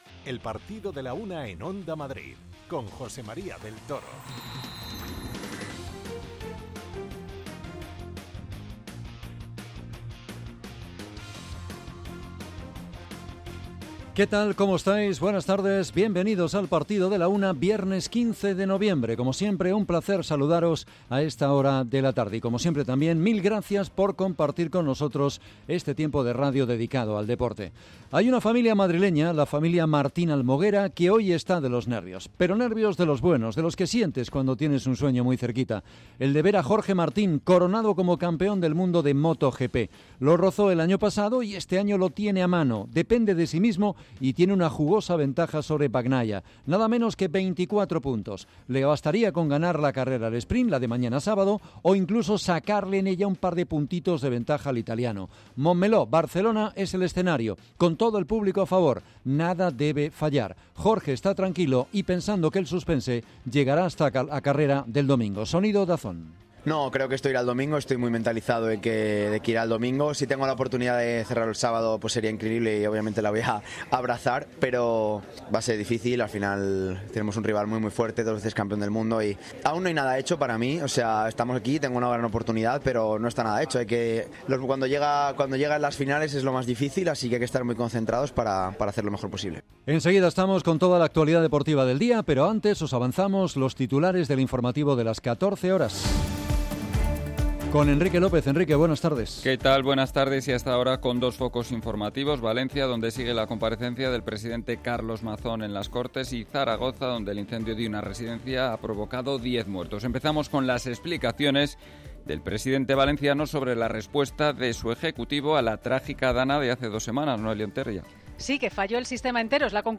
Escuchamos a Jorge, a Bagnaia y a los vecinos de su localidad natal, orgullosos por su gran temporada.